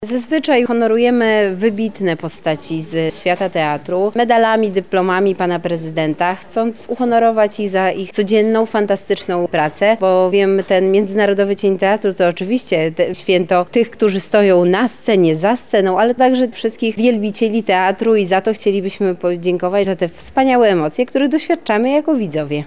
Bartłomiej Bałaban– mówi Bartłomiej Bałaban, członek zarządu województwa lubelskiego